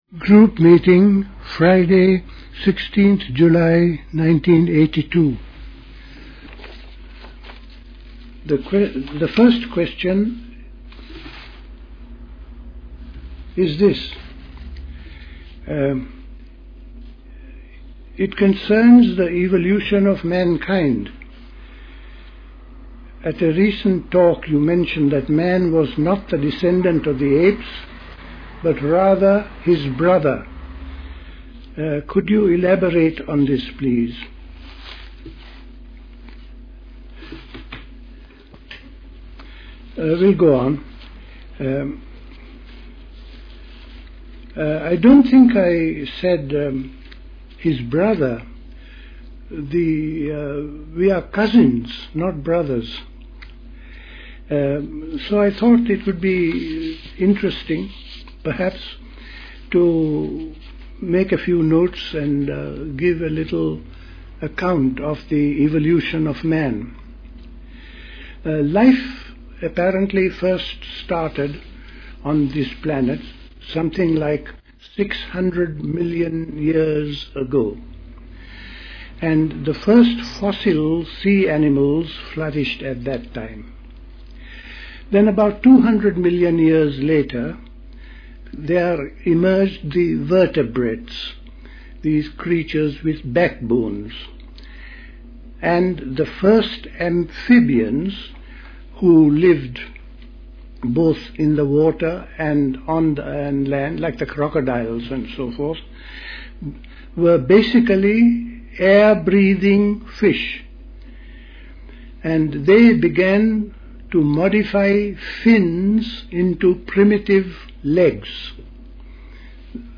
A talk given